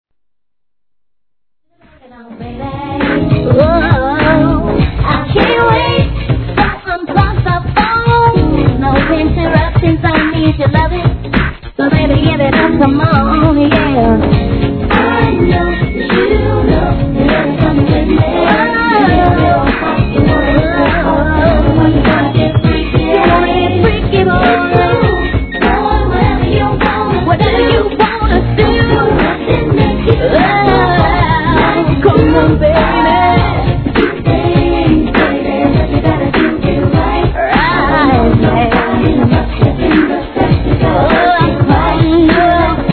1. HIP HOP/R&B
1.ORIGINAL VERSION -